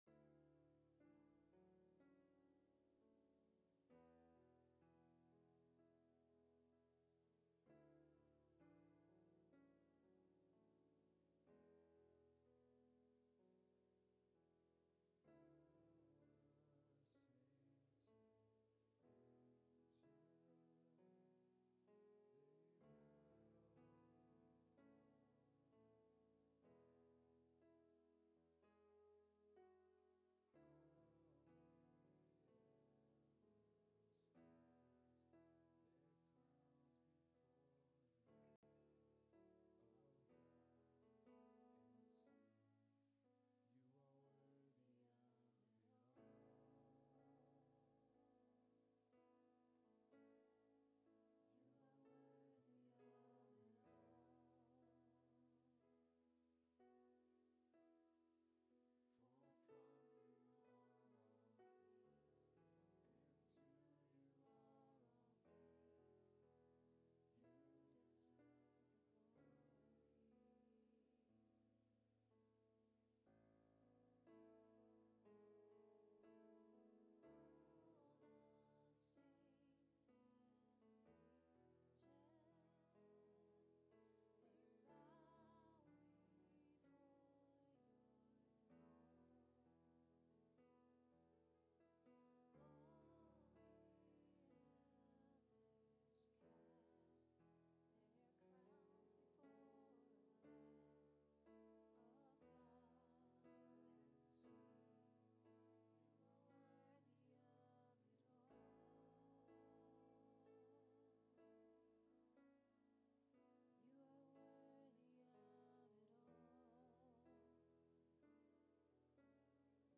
Whole Service